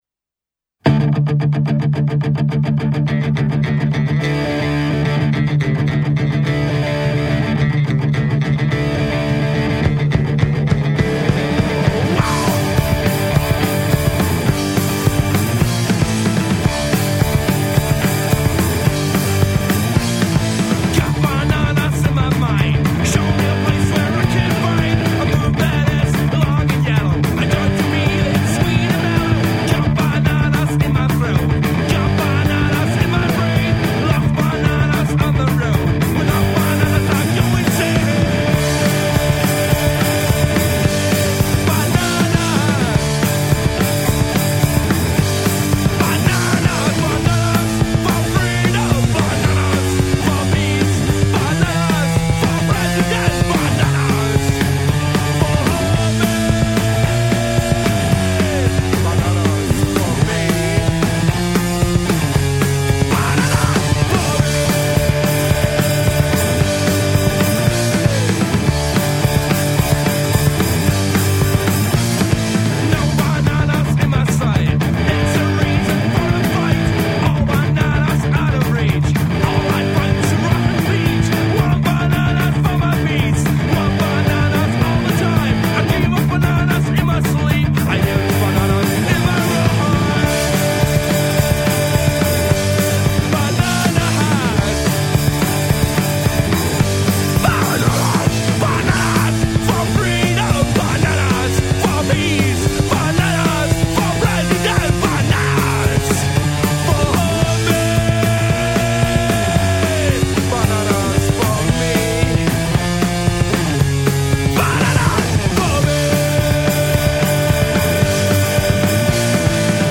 PUNKROCK
Gesang
Gitarre
Schlagzeug